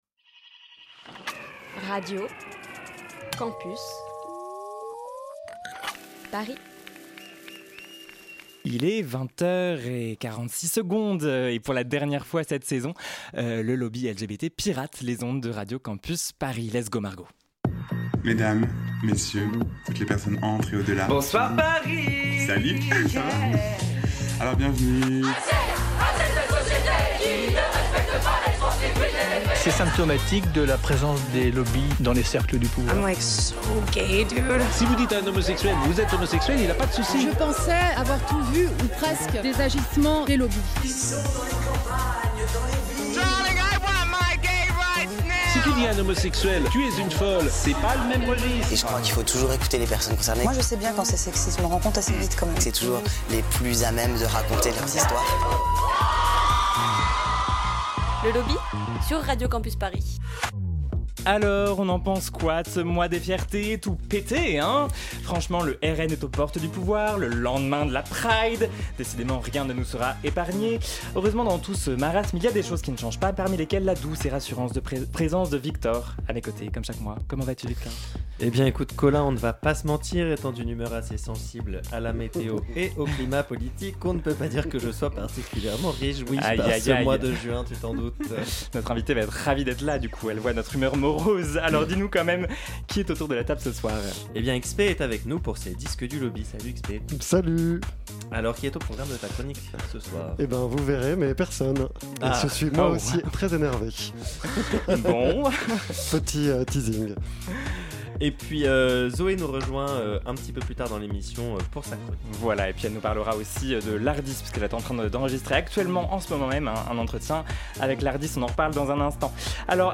Partager Type Magazine Société mardi 18 juin 2024 Lire Pause Télécharger Alors que les Législatives anticipées auront lieu dans moins de deux semaines, on décrypte les enjeux queers de la campagne.